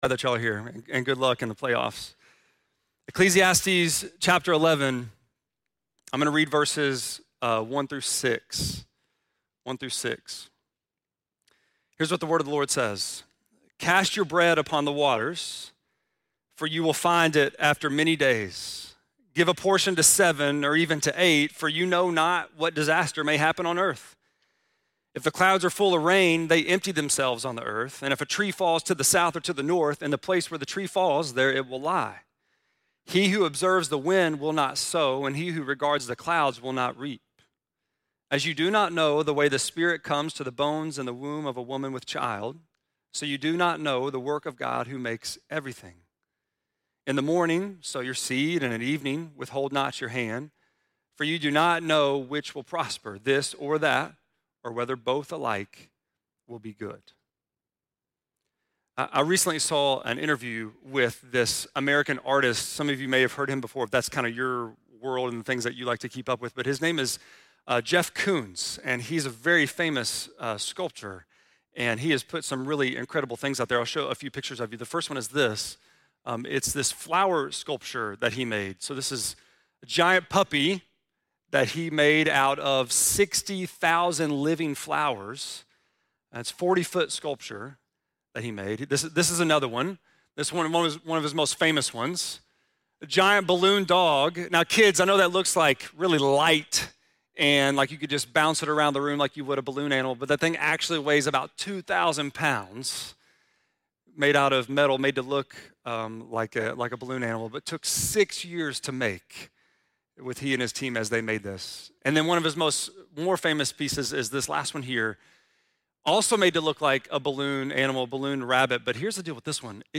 2.23-sermon.mp3